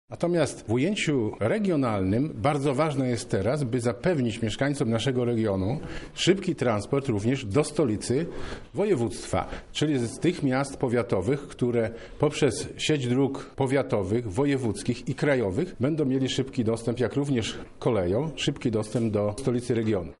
– tłumaczy Sławomir Sosnowski, marszałek województwa lubelskiego.